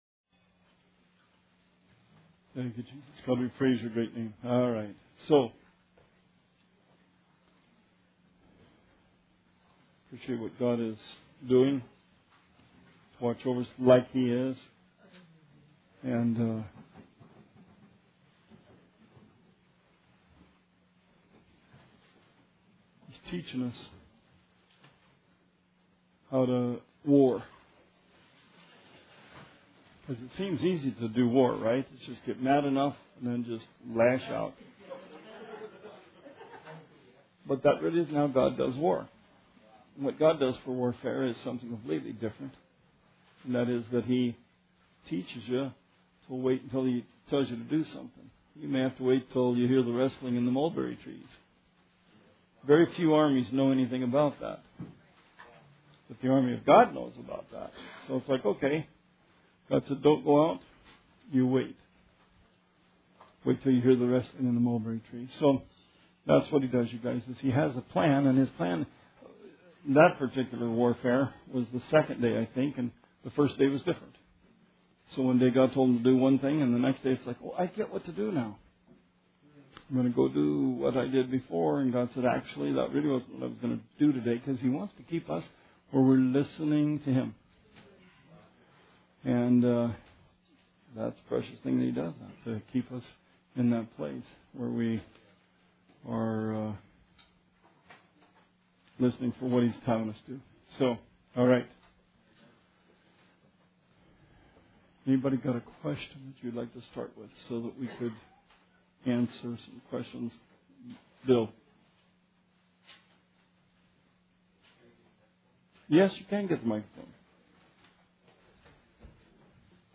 Bible Study 2/1/17